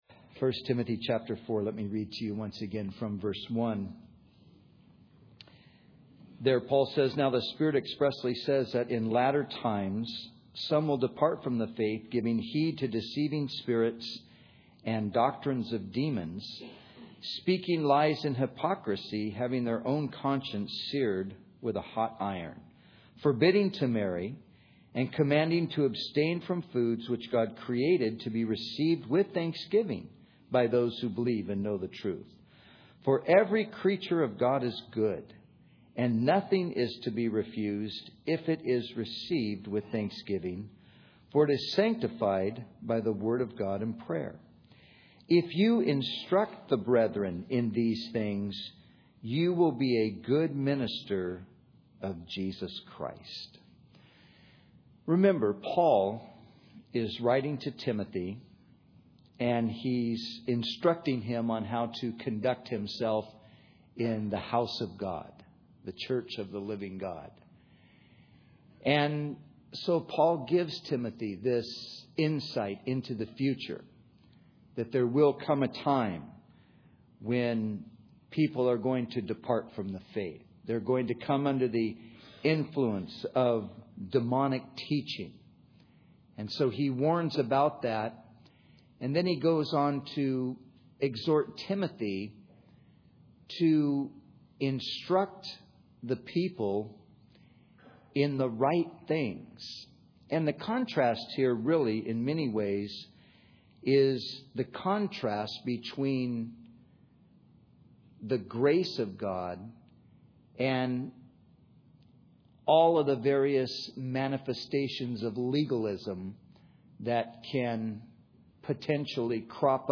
In this sermon, the teacher discusses the story of a man who signed a covenant with his church to abstain from various activities in order to be right with God.